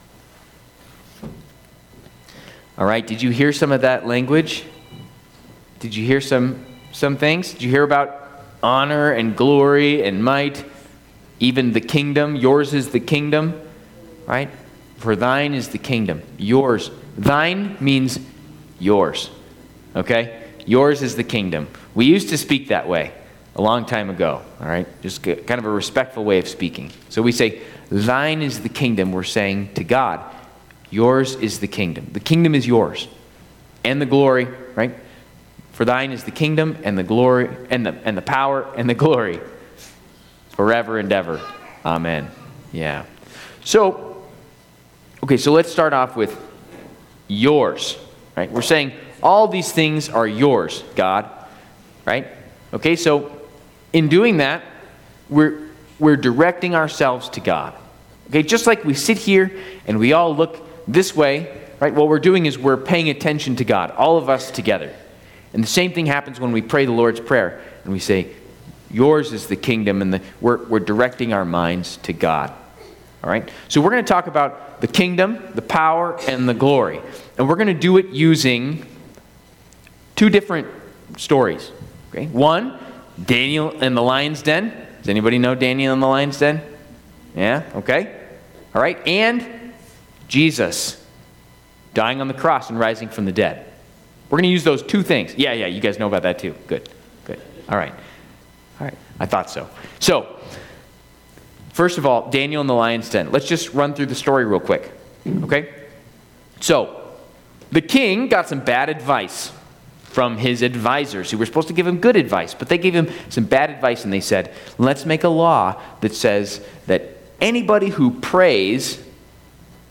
Mid-week Lenten service and school chapel